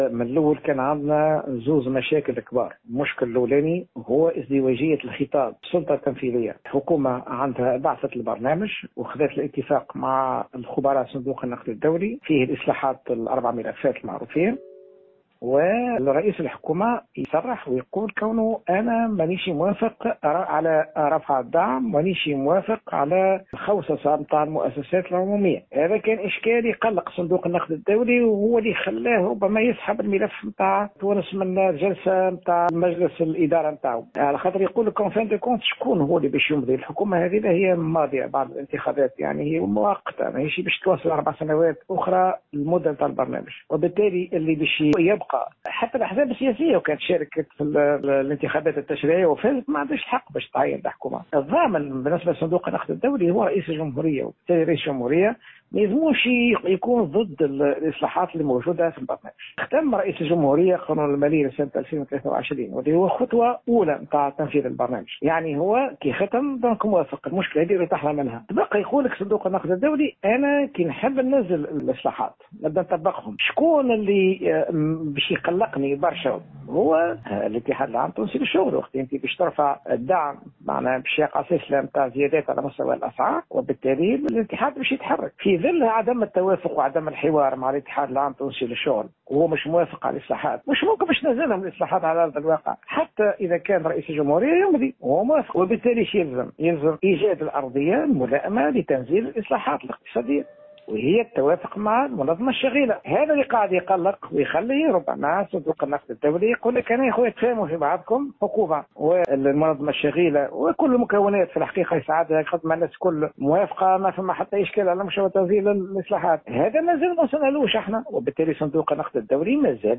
on pourrait faire face au scénario libanais [Déclaration]